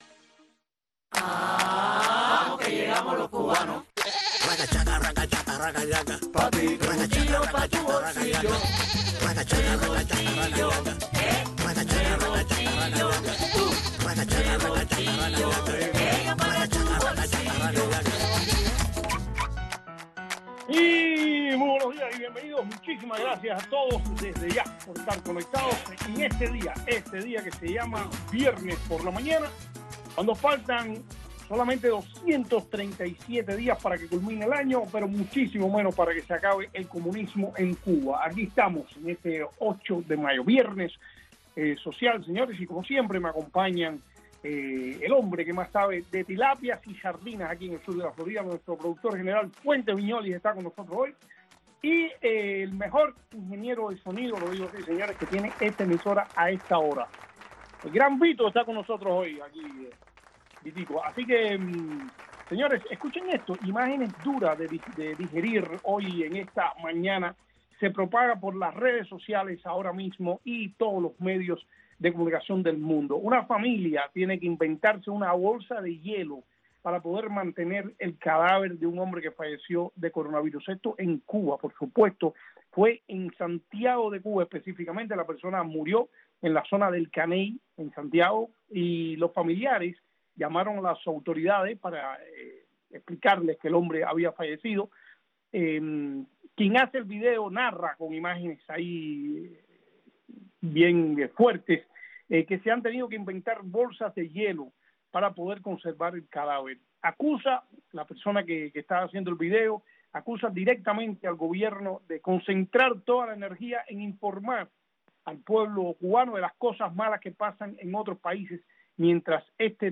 entrevistas, anécdotas y simpáticas ocurrencias